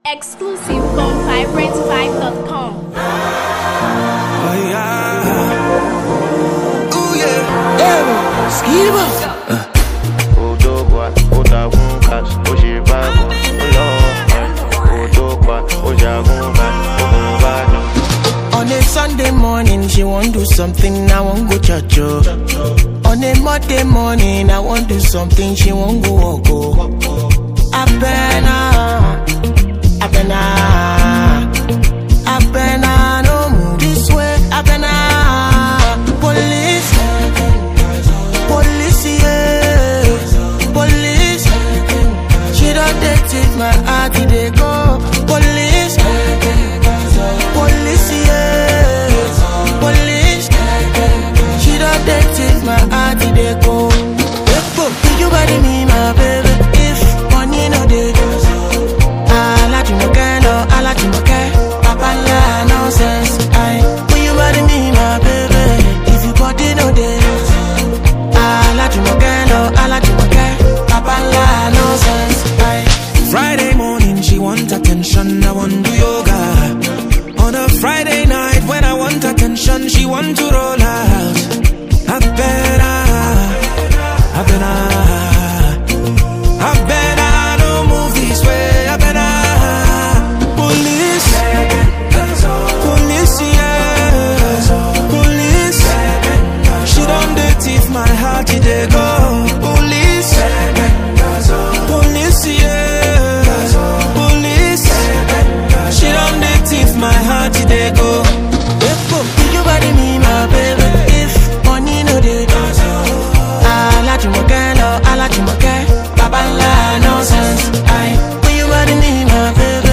a compelling fusion of Afrobeat rhythms and soulful melodies